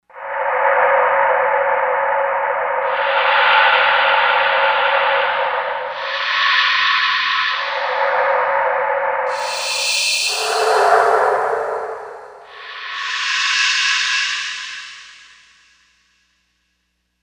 Synthesizer module
Korg M1Rex factory patches mp3 audio demos
95 Noise
95 Noise.mp3